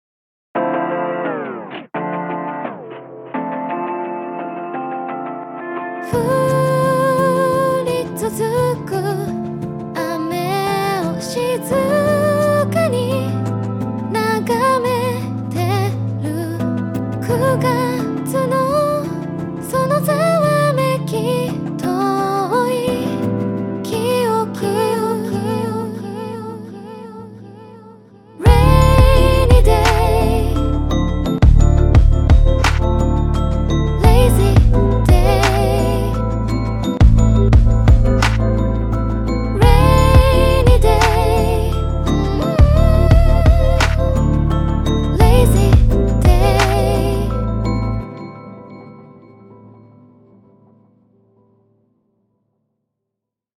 『Rainy Day』今日は１日雨だったのでそんな感じを２コードで作りました 歌はSynthesizerVの永夜Minusさん